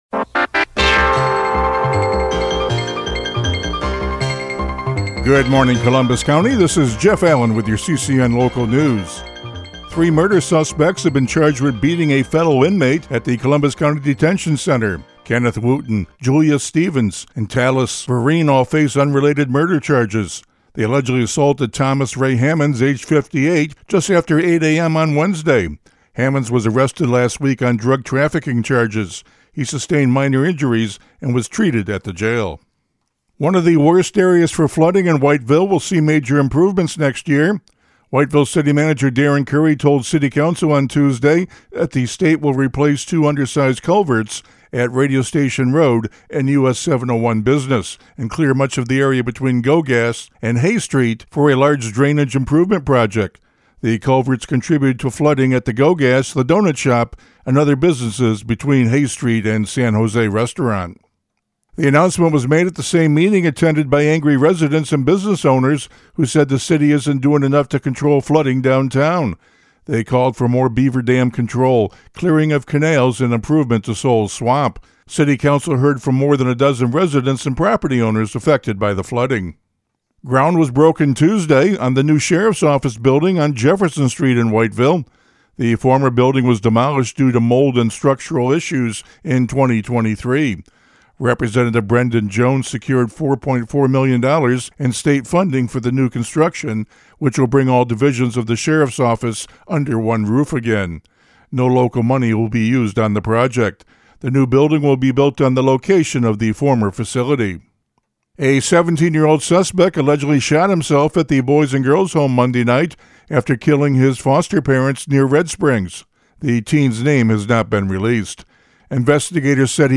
CCN Radio News — Morning Report for October 30, 2025